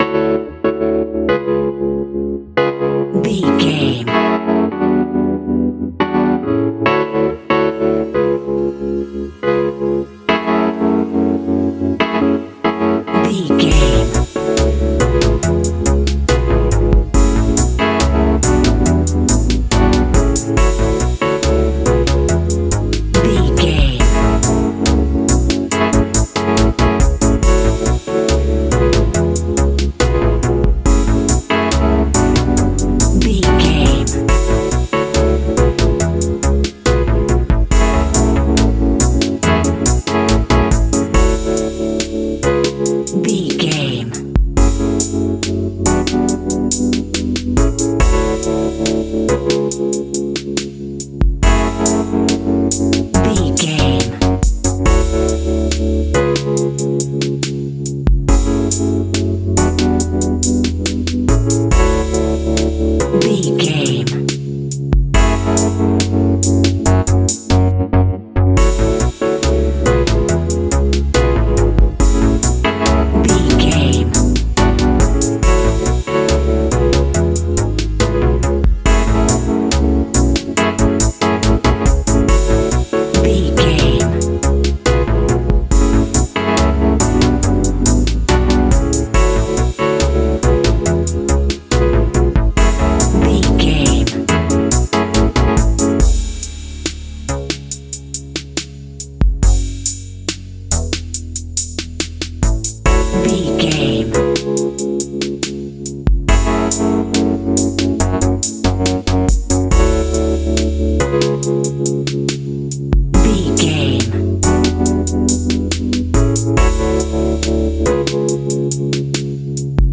Ionian/Major
F♯
chilled
laid back
Lounge
sparse
new age
chilled electronica
ambient
atmospheric
instrumentals